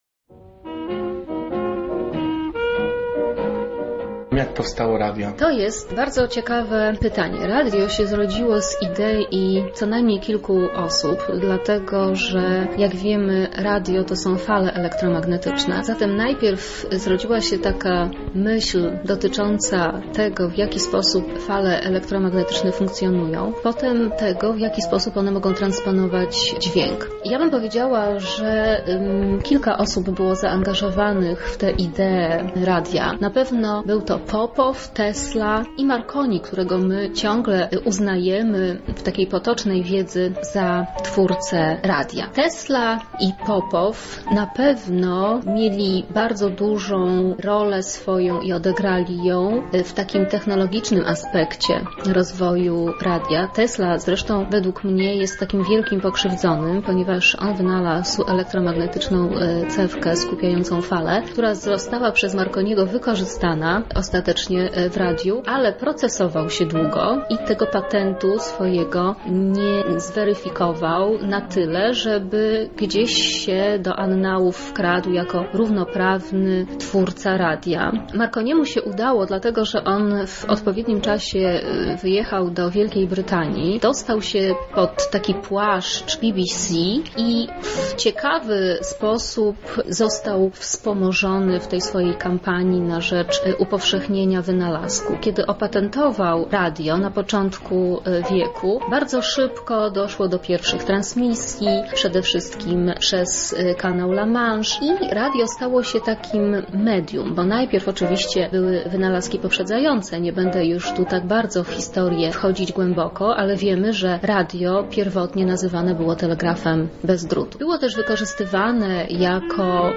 radio obrazek
radio-obrazek.mp3